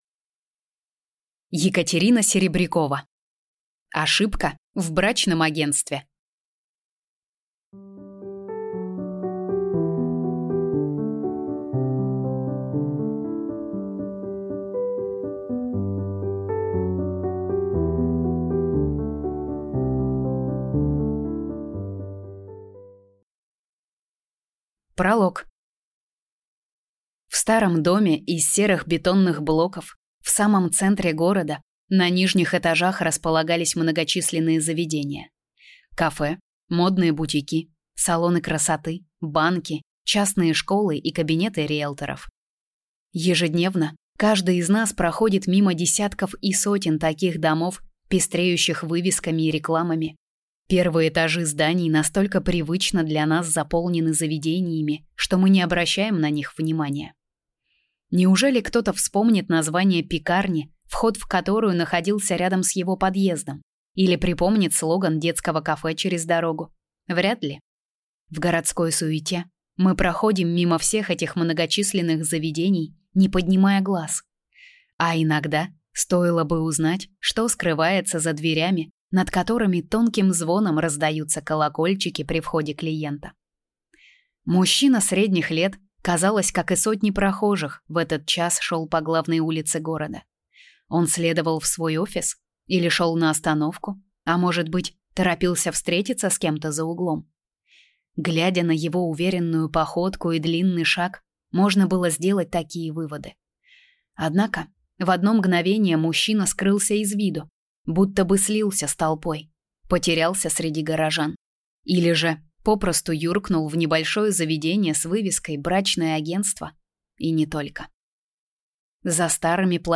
Аудиокнига Ошибка в брачном агентстве | Библиотека аудиокниг